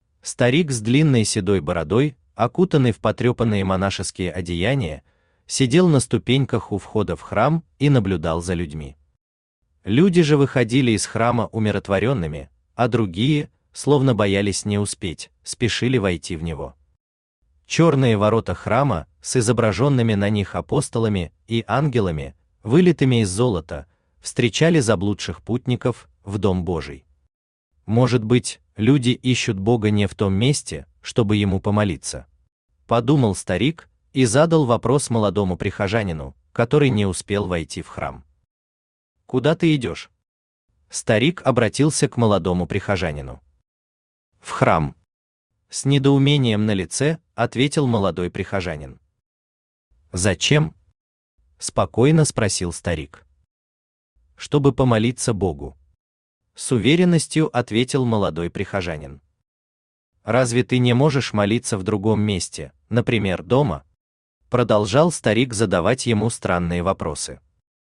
Аудиокнига Храм | Библиотека аудиокниг
Aудиокнига Храм Автор Виталий Александрович Кириллов Читает аудиокнигу Авточтец ЛитРес.